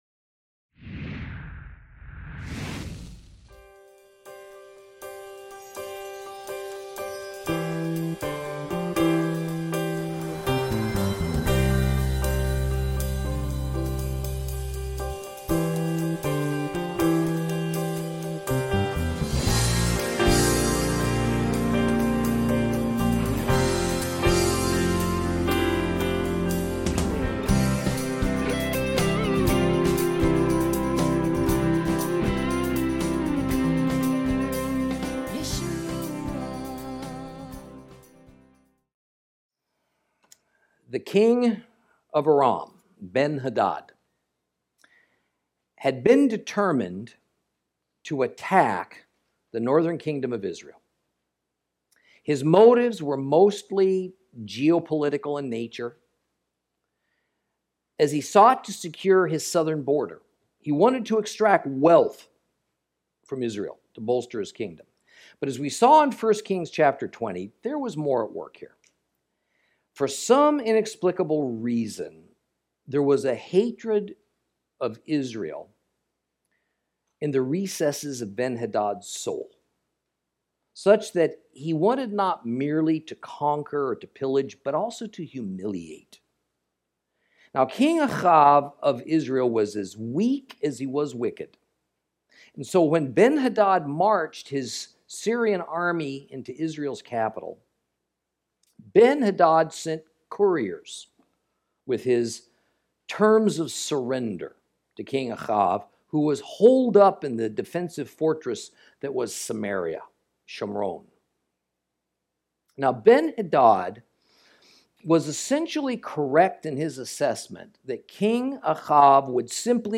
Lesson 33 Ch20 Ch21 - Torah Class